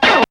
ZAP DOOT.wav